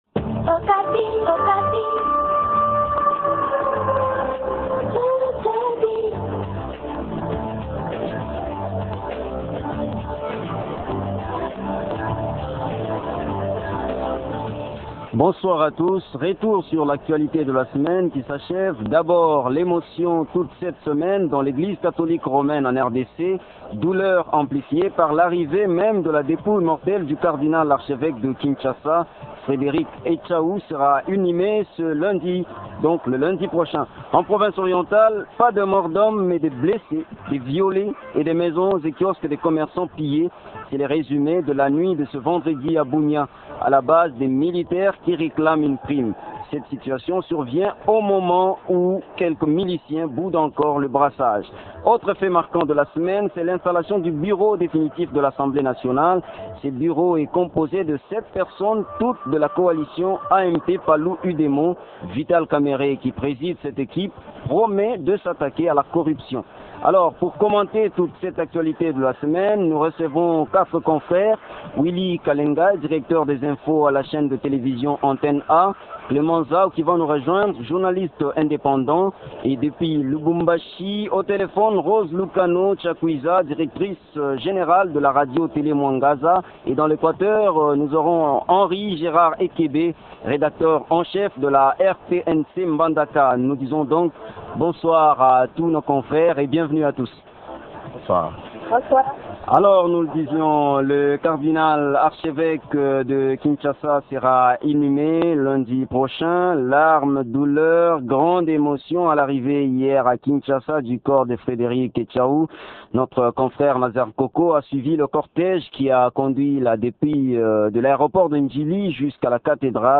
Trois thèmes à débattre ce soir: